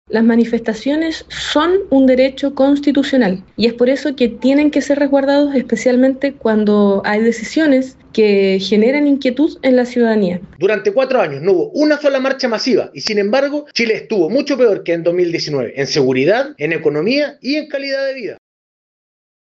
Desde el Frente Amplio, la diputada Coca Ñanco defendió el derecho a la movilización, mientras que, desde el Partido Nacional Libertario, el diputado Hans Marowski cuestionó que este tipo de marchas no se hubieran manifestado con la misma intensidad durante la administración anterior.